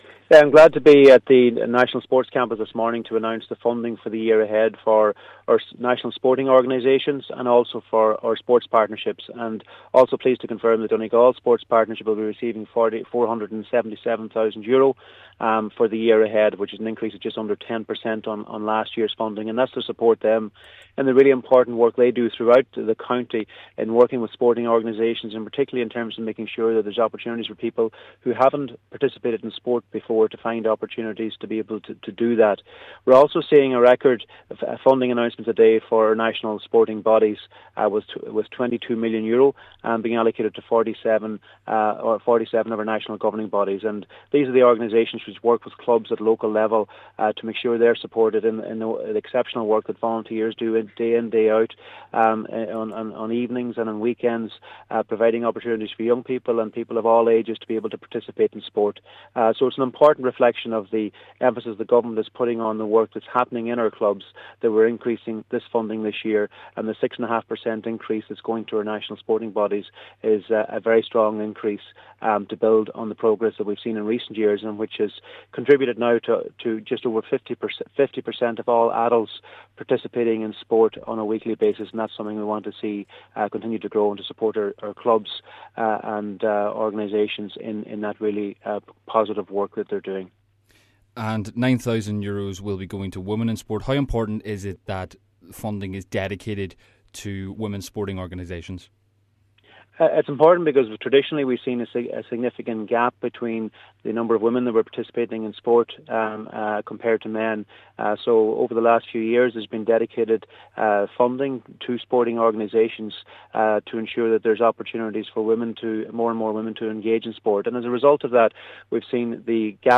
Minster McConalogue says this will support important work: